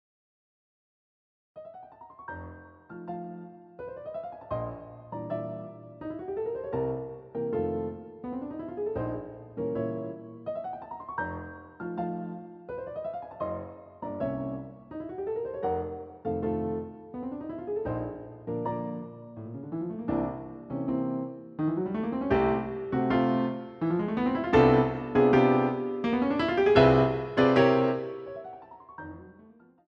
using the stereo sampled sound of a Yamaha Grand Piano.